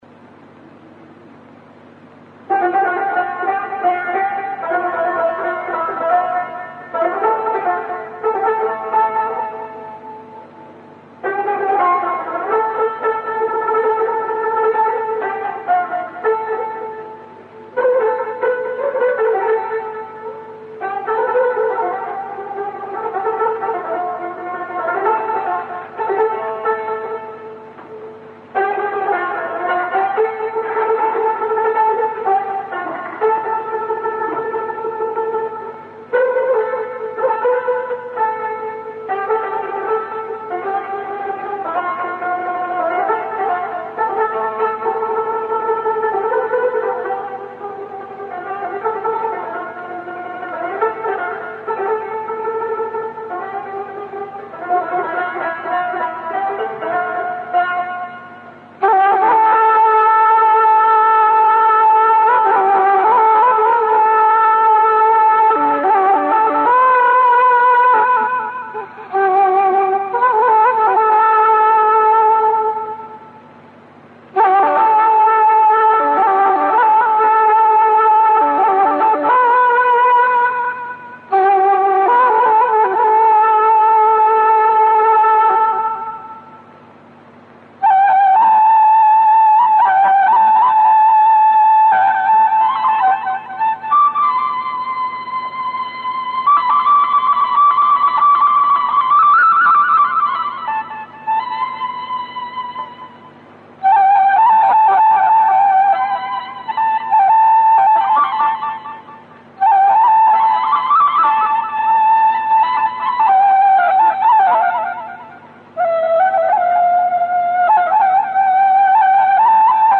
persian art music
Persian Tar virtuoso
Persian Ney